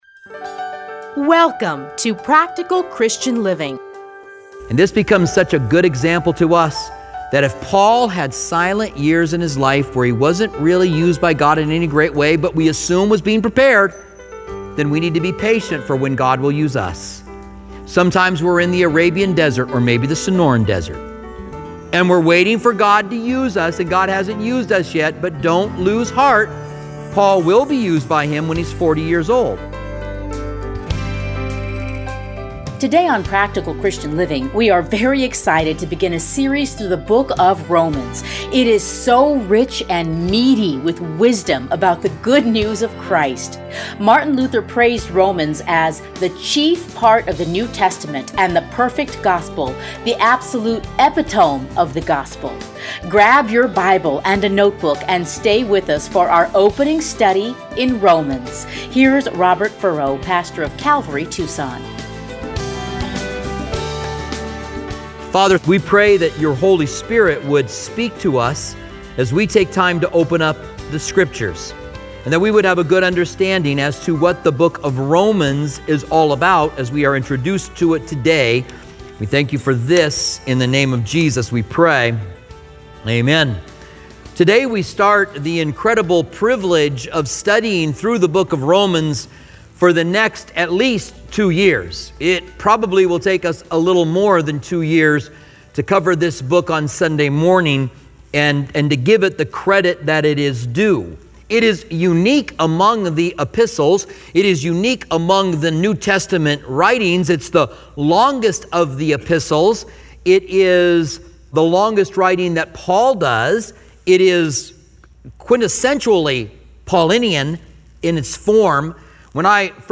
Listen here to his commentary on Romans.